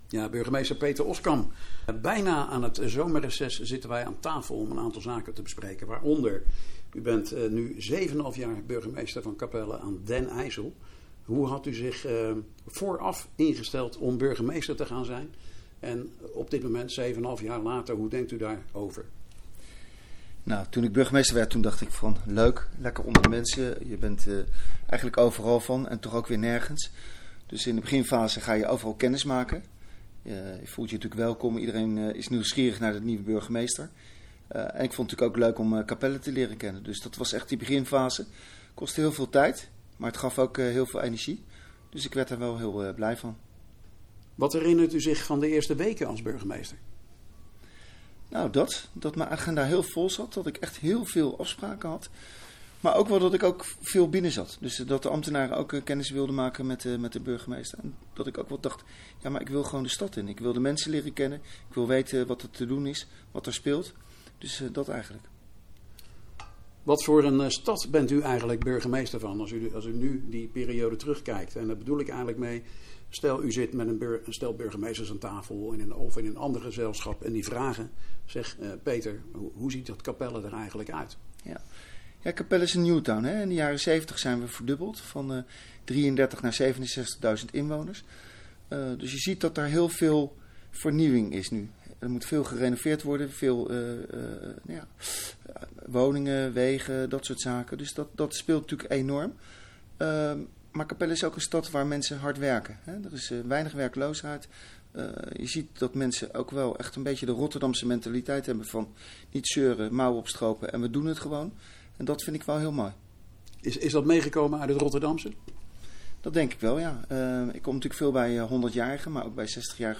in gesprek met burgemeester Peter Oskam over Capelle en 7,5 jaar burgemeester